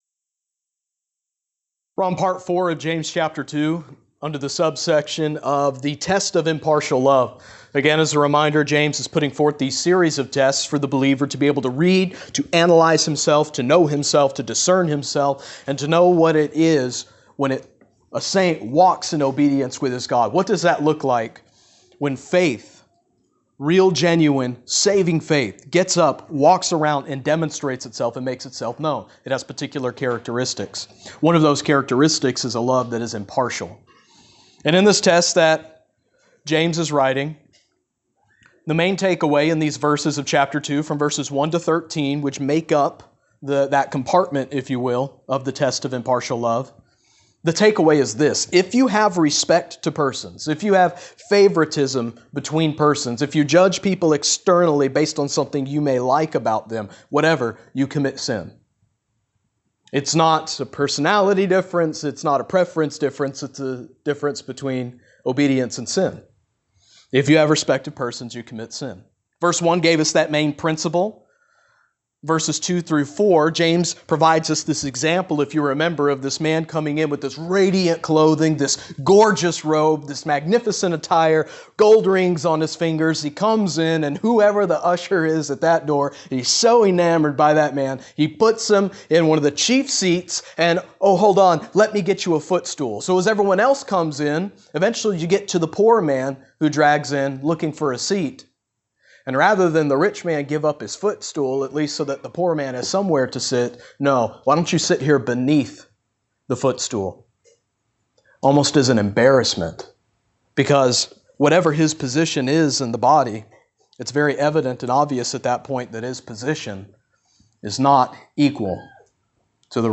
Jm2 Pt 4 The Royal Law | SermonAudio Broadcaster is Live View the Live Stream Share this sermon Disabled by adblocker Copy URL Copied!